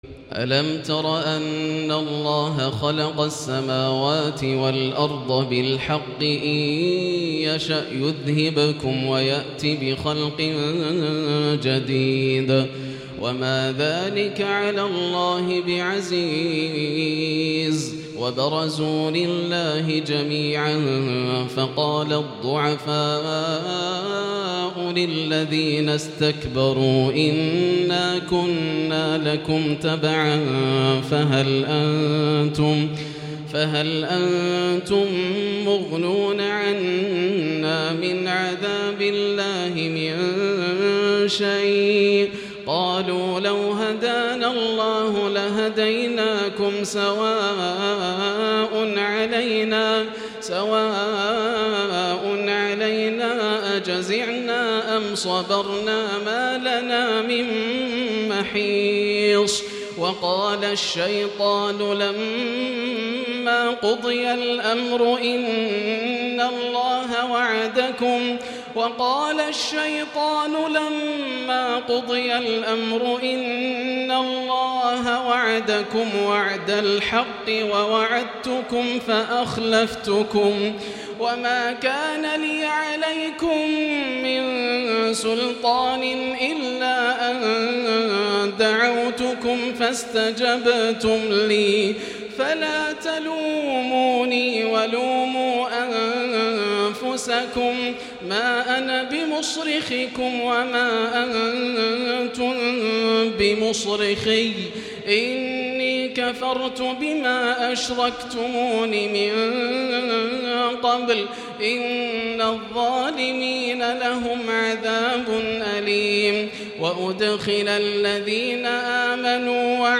(وَقَالَ الشَّيْطَانُ لَمَّا قُضِيَ الْأَمْرُ) > الروائع > رمضان 1438هـ > التراويح - تلاوات ياسر الدوسري